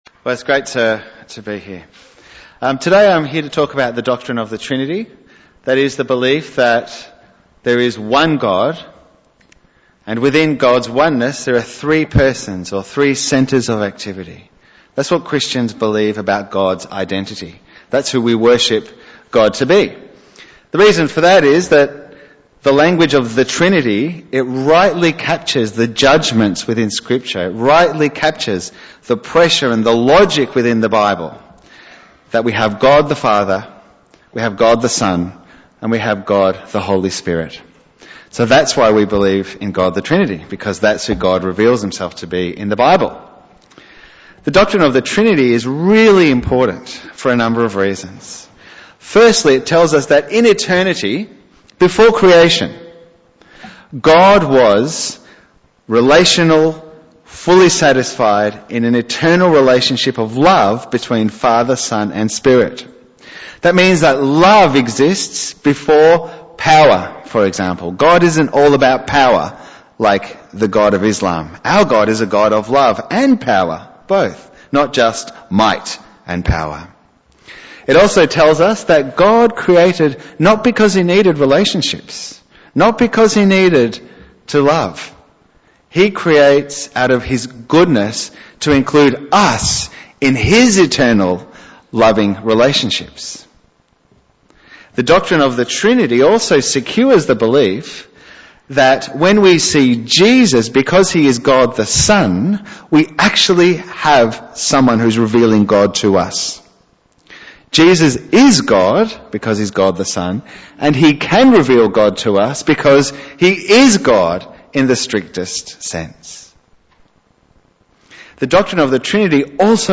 Bible Text: Galatians 4:4-8 | Preacher